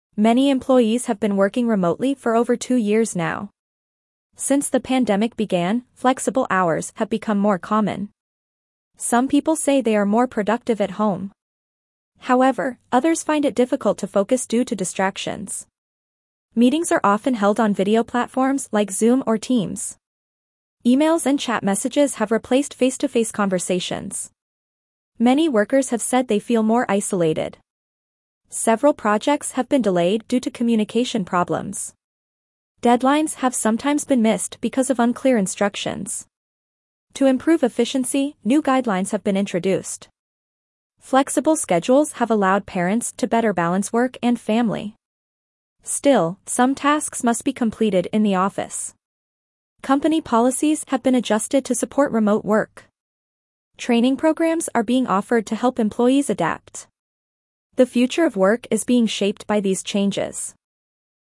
Dictation B2 - Remote Work Challenges
Your teacher will read the passage aloud.